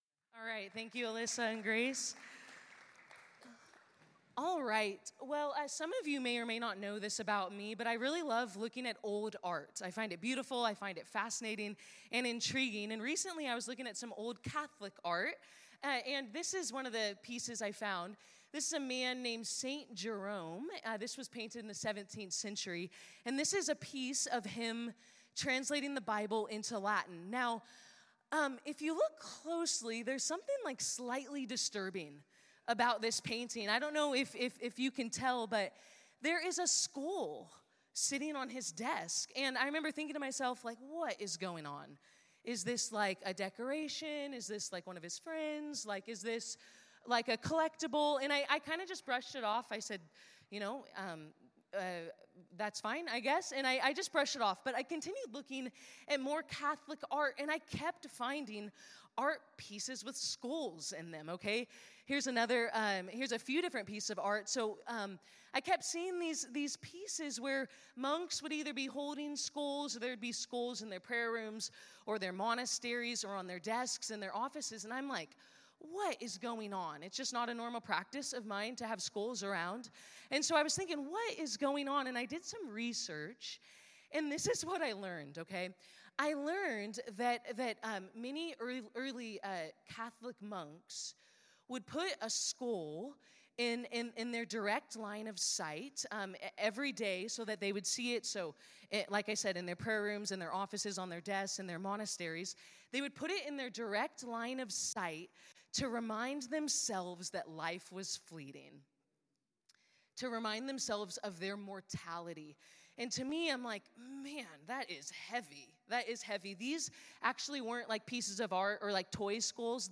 This talk was given in chapel on Wednesday, March 5th, 2025.